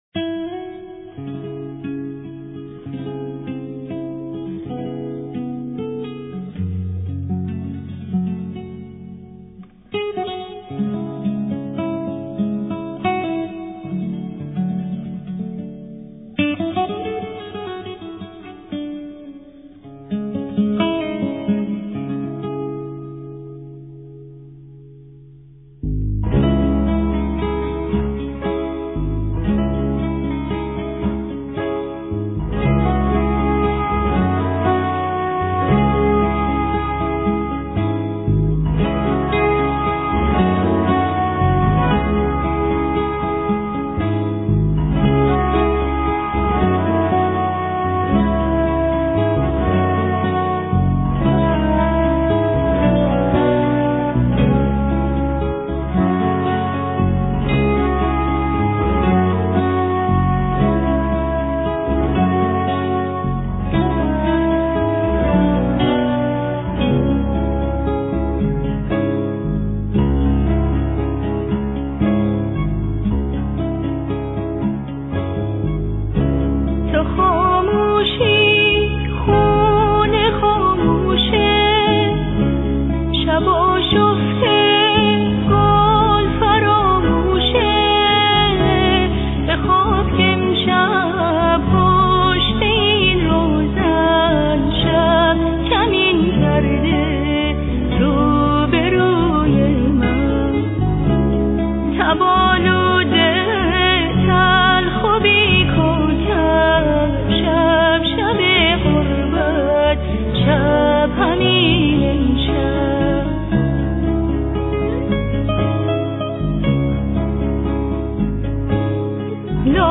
ترانه ساز: (محلی)